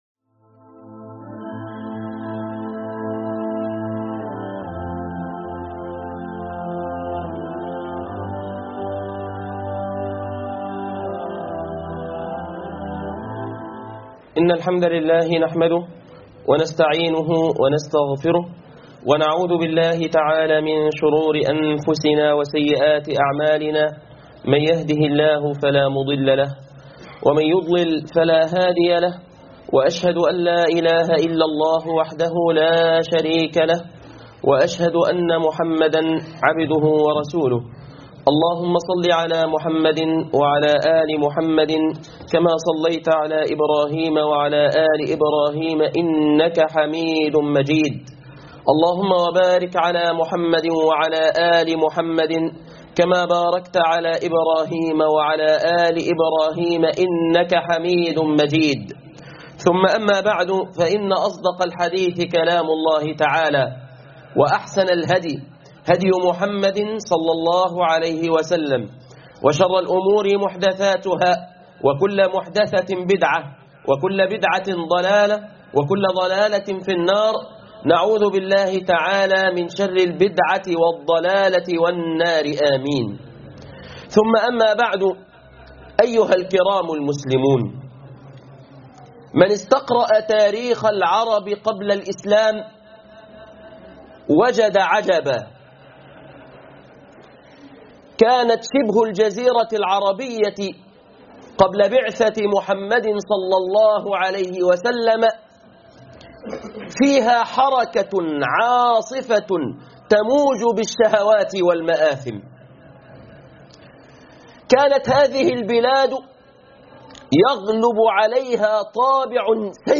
خفافيش الظلام خطبة الجمعة